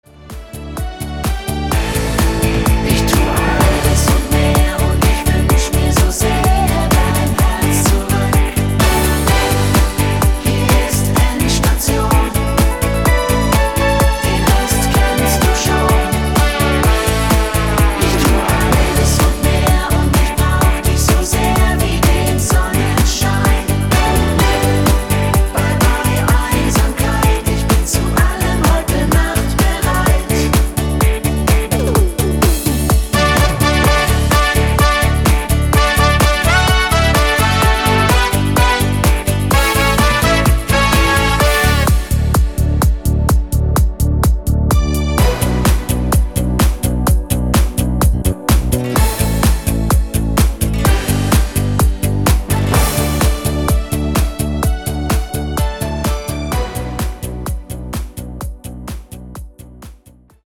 gut-tanzbar